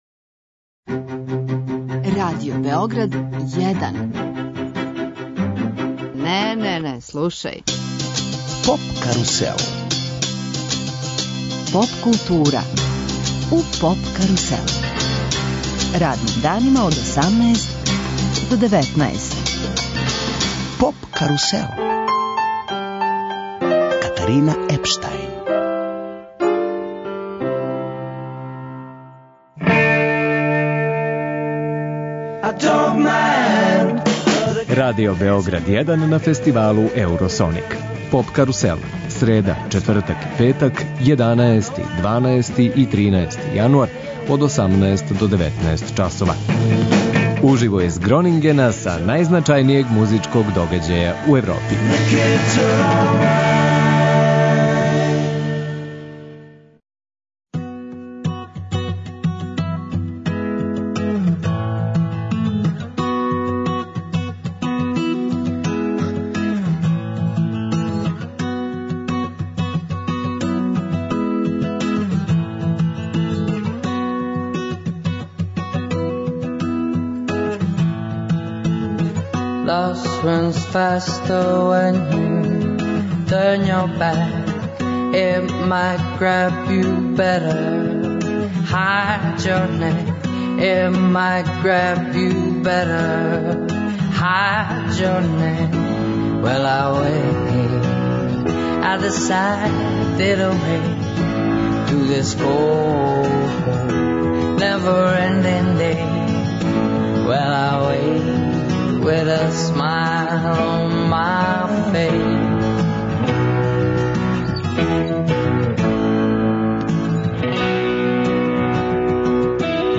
Радио Београд 1 ексклузивно емитује емисије уживо са водећег европског музичког фестивала Еуросоник.